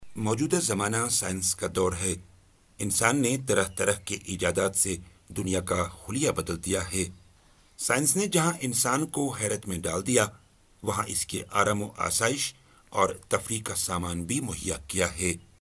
UR AG EL 02 eLearning/Training Male Urdu